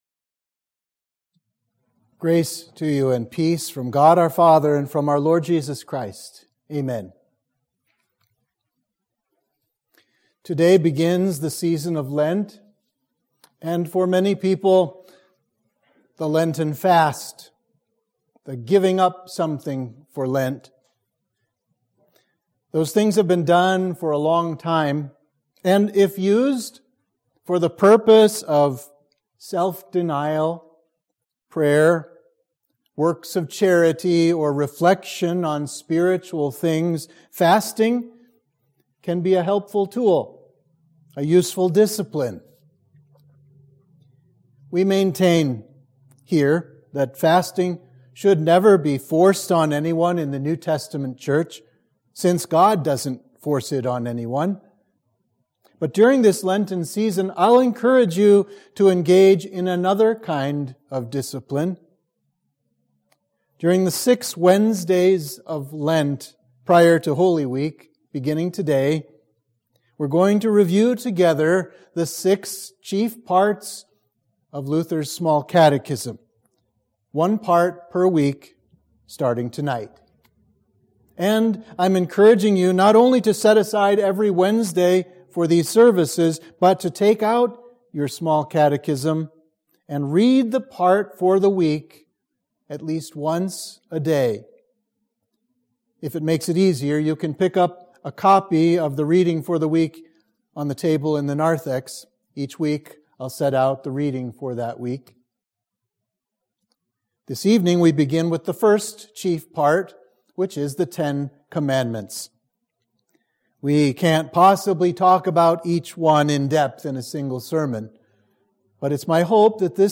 Sermon for the First Day of Lent